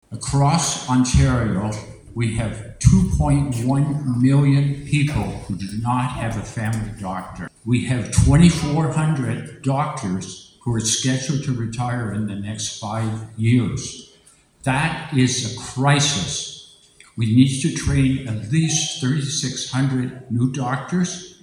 Four candidates appeared at a forum last Tuesday, hosted by the St. Thomas & District Chamber of Commerce.